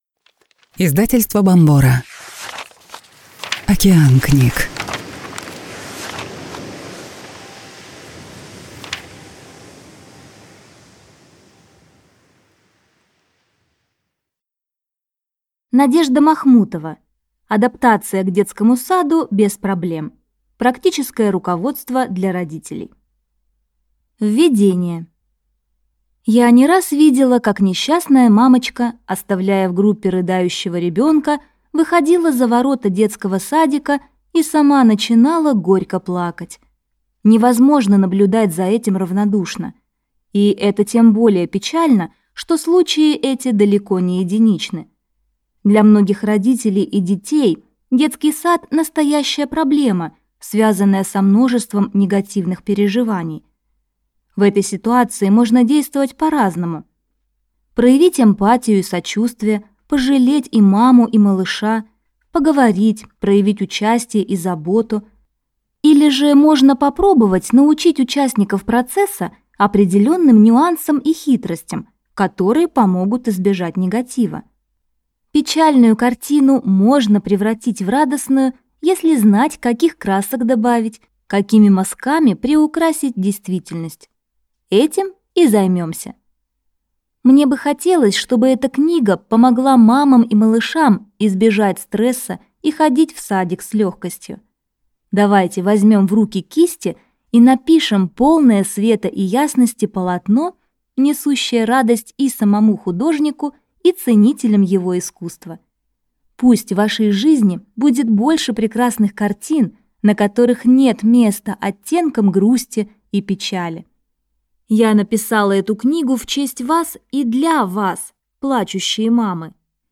Аудиокнига Адаптация к детскому саду без проблем | Библиотека аудиокниг